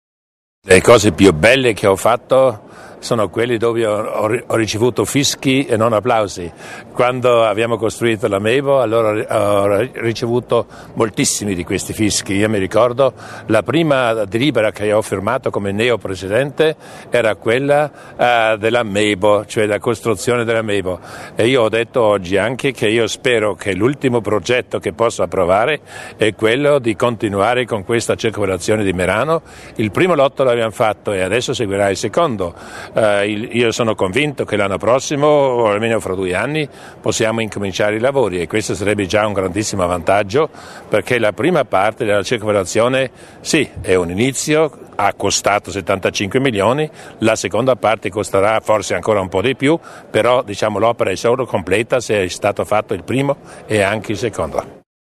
Il Presidente Durnwalder spiega l'importanza della circonvallazione di Merano